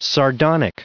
added pronounciation and merriam webster audio
926_sardonic.ogg